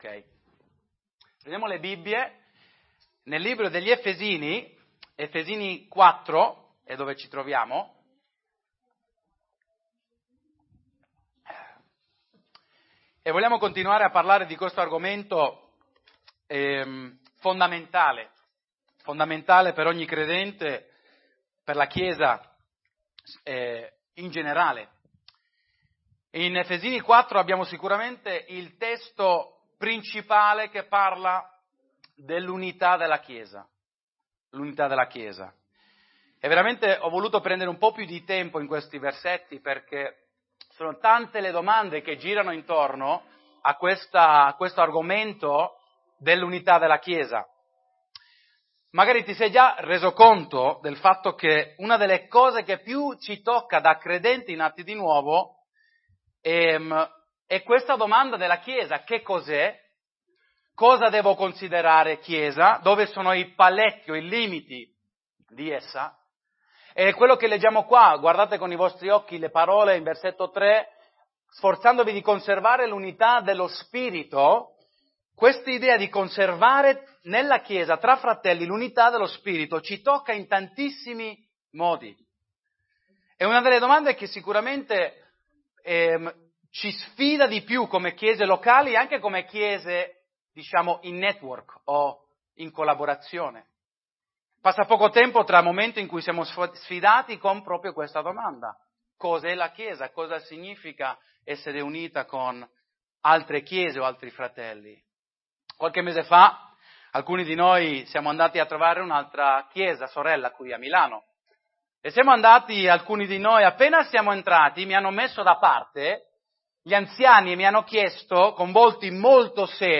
Tutti i sermoni